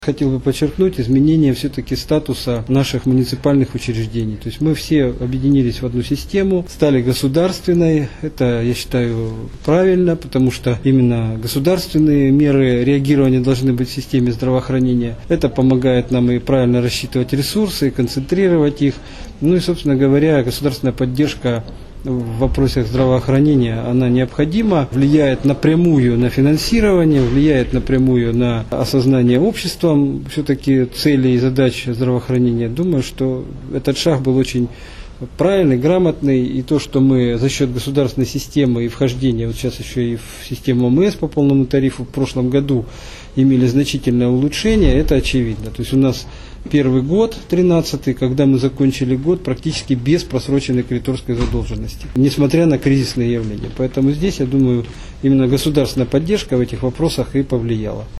Такие данные озвучили на публичном отчете о результатах деятельности Департамента здравоохранения региона.